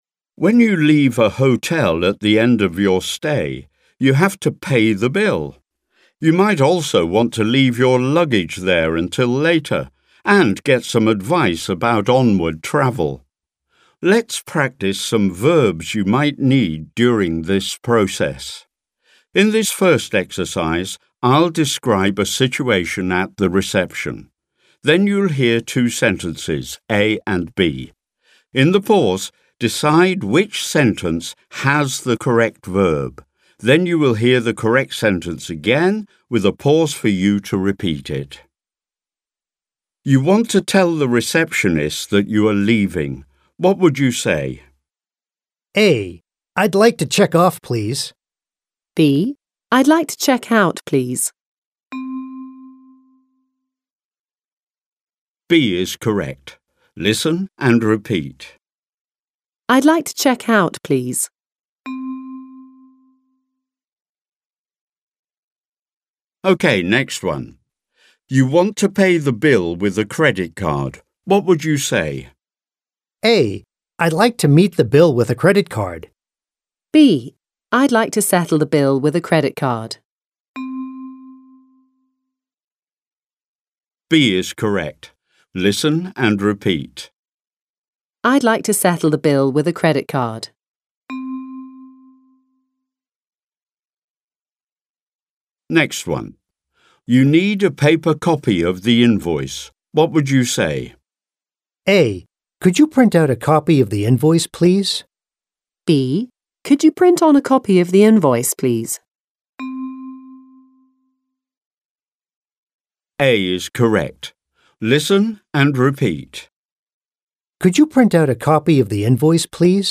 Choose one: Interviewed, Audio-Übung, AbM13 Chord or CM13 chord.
Audio-Übung